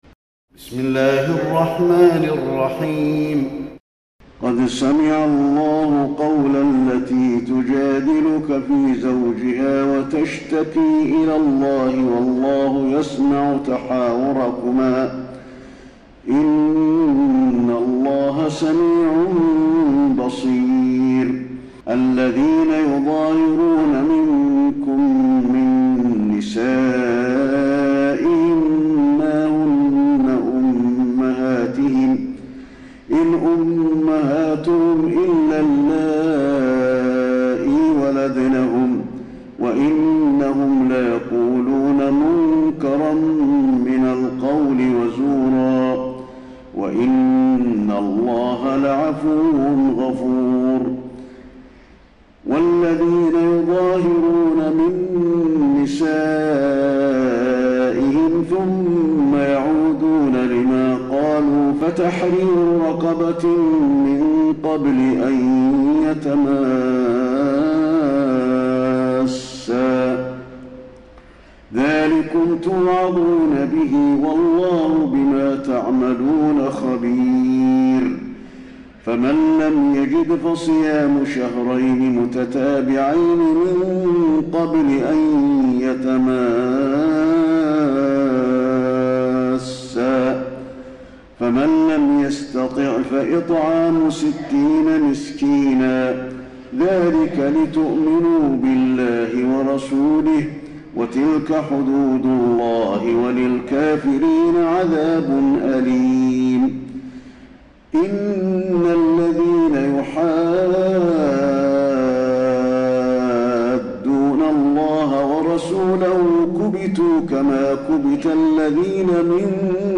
تراويح ليلة 27 رمضان 1432هـ من سورة المجادلة الى الصف Taraweeh 27 st night Ramadan 1432H from Surah Al-Mujaadila to As-Saff > تراويح الحرم النبوي عام 1432 🕌 > التراويح - تلاوات الحرمين